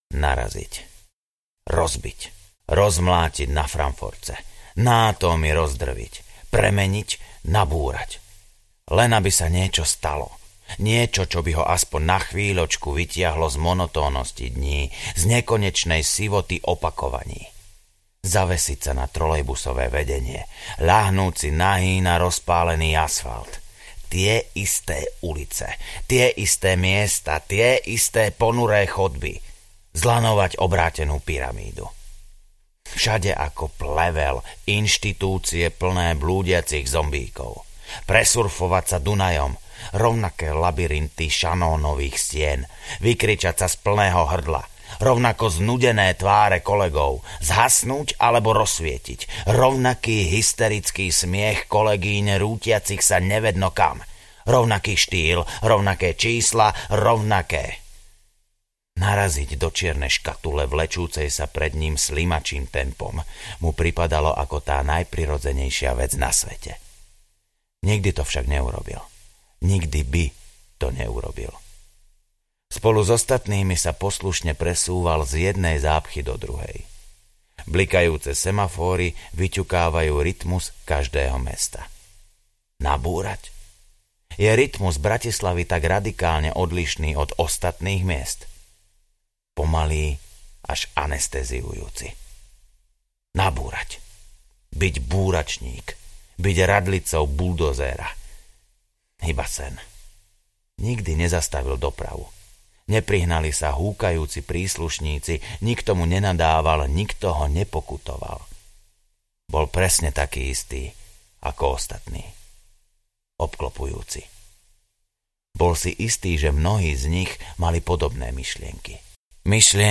Spolu audiokniha
Ukázka z knihy